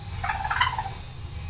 Енот – одно из самых громких животных, во время брачного сезона он кричит,
мяукает, рычит и свистит.
Еноты очень забавно урчат.
chirp.au